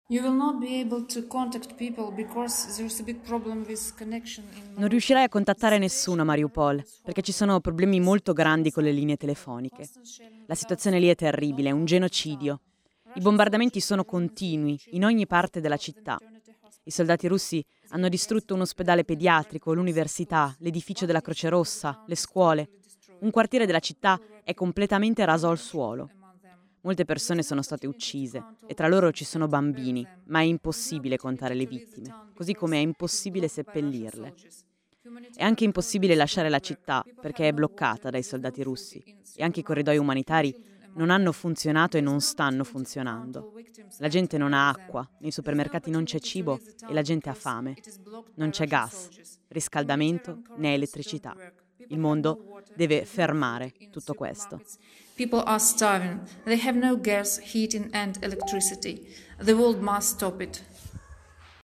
Questa è la sua testimonianza.